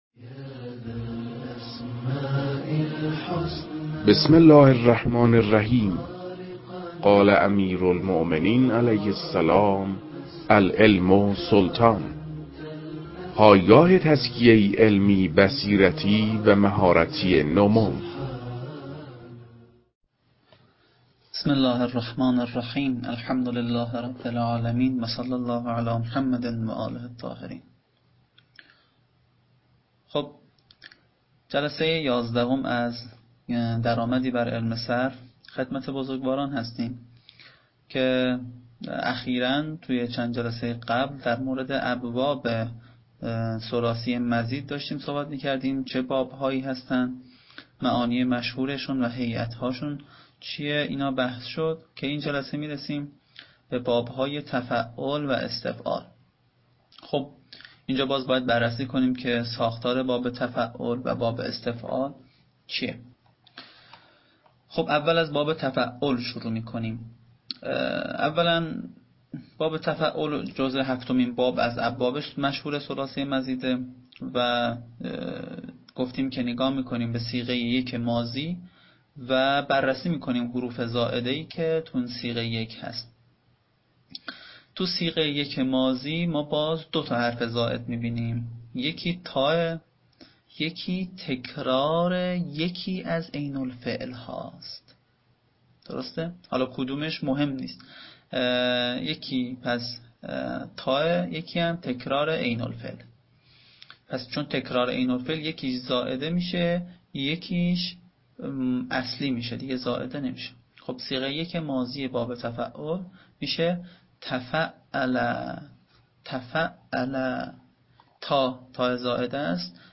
در این بخش، کتاب «درآمدی بر صرف» که اولین کتاب در مرحلۀ آشنایی با علم صرف است، به صورت ترتیب مباحث کتاب، تدریس می‌شود.